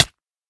sounds / damage / hit1.ogg
hit1.ogg